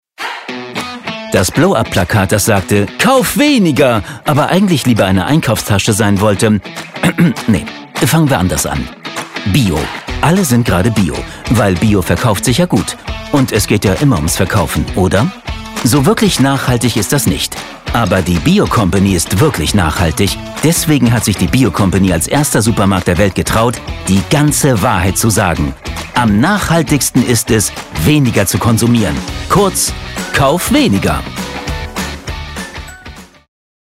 dunkel, sonor, souverän, sehr variabel
Mittel minus (25-45)
Commercial (Werbung)